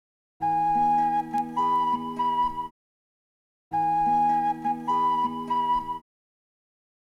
アナログＬＰレコードのノイズ処理
しかし、レコードには大量のプチプチノイズ、或いは大きめのブチッノイズが入っています。
処理前の音と処理後の音を続けて２秒ずつ。
ノイズ処理ソフト（キラーノイズとか）でやると音質が変わってしまいますが、この方法で処理すると原曲のままほとんど音質を損ないません。